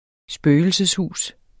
spøgelseshus substantiv, intetkøn Bøjning -et, -e, -ene Udtale [ ˈsbøːjəlsəsˌ- ] Betydninger 1. hus befolket af spøgelser eller andre overnaturlige væsener Spøgelseshuset består af en labyrint, som man skal igennem.